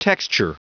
Prononciation audio / Fichier audio de TEXTURE en anglais
Prononciation du mot : texture